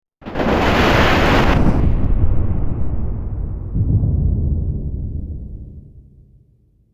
Efecto especial (trueno)
trueno
tronar
Sonidos: Especiales